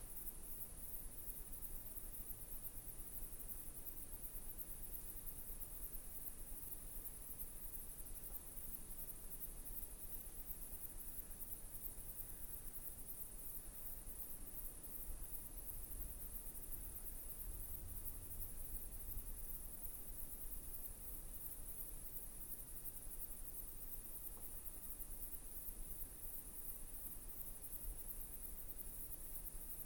Wir benötigen eine Geräuschkulisse, die zu unserem nächtlichen Friedhof passt: das Rauschen von Wind, das Knarren von alten Bäumen, eine Eule,…
In diesem kostenlosen CC0 Asset finden sich neben Schrittgeräuschen (Footsteps), die wir bald noch verbauen werden, auch Umgebungsgeräusche aus der Natur (Nature Ambience).
Ich habe daher die Datei mit Audacity von WAV zu einem Mono-MP3 konvertiert, womit es sich dann richtig anhört.
Ambiance_Night_Loop_Stereo_Mono.mp3